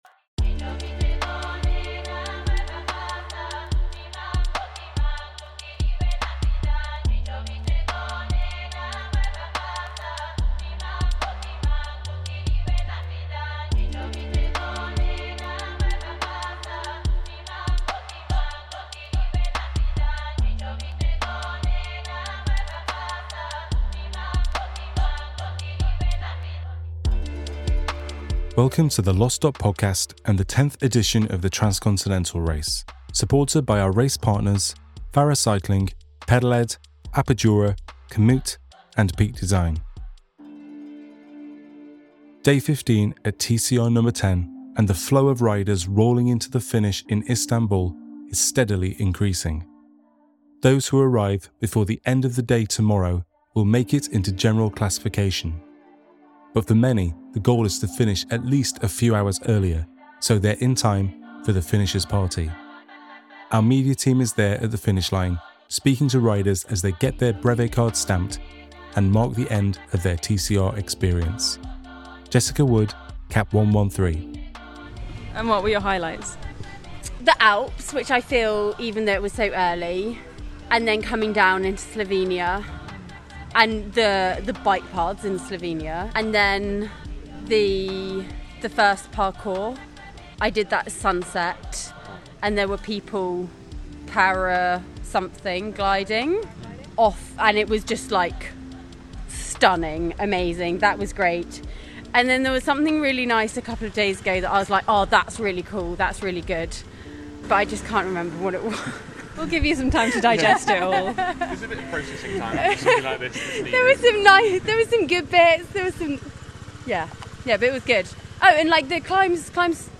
TCRNo10 | Day 15 Aug 06, 2024, 02:00 PM Headliner Embed Embed code See more options Share Facebook X Subscribe TCRNo10 // Day 15 The day before the Finishers Party sees riders pushing hard to remain in General Classification. The Media Team is on hand to speak to the consistent flow of finishers arriving in Istanbul, and hear from their library of stories. From TCR veterans to those who have just completed the Race for the first time, hear sights and sounds of the TCRNo10 Finish as they celebrate this incredible achievement.